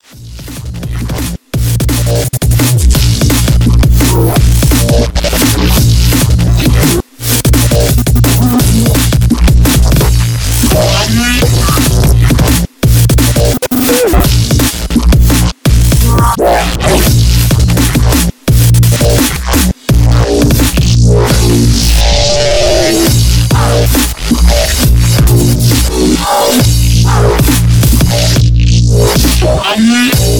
Elektronisk musik